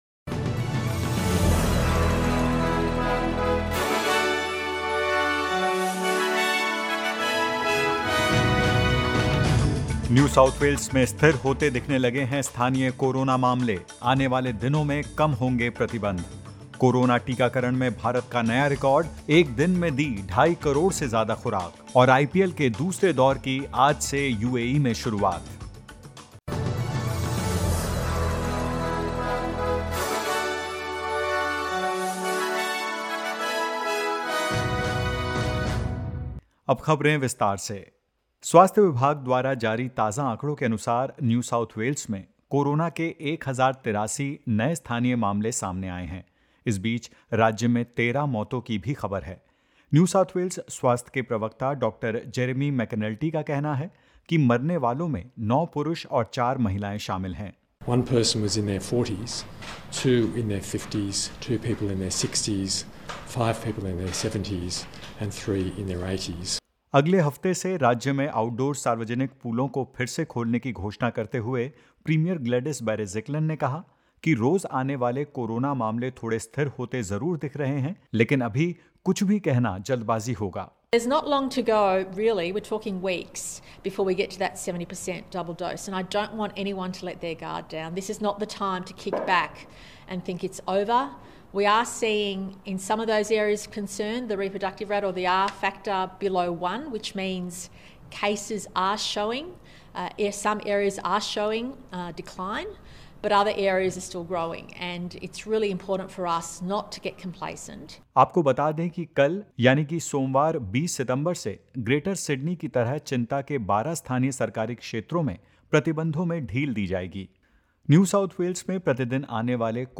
In this latest SBS Hindi News bulletin of Australia and India: Victoria releases roadmap for reopening; ACT reports 17 new local cases of COVID-19 and more.